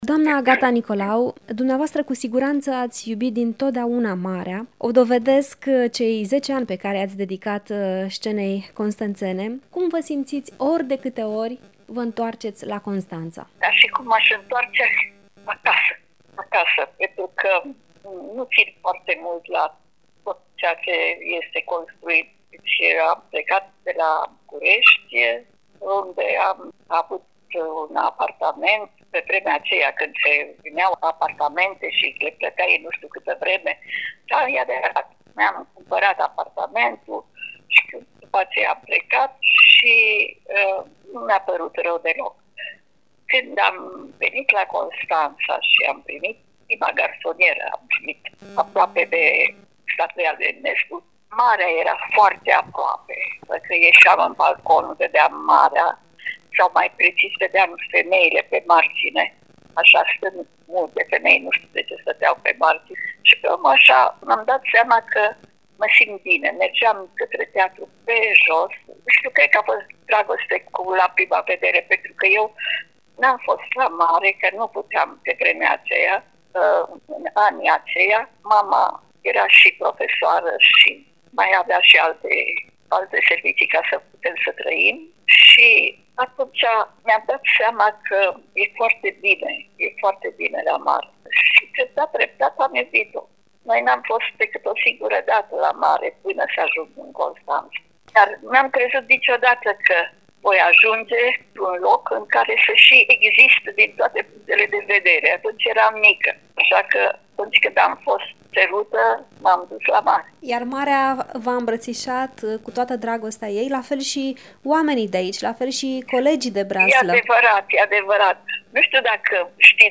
În cadrul unui interviu emoționant, înregistrat prin telefon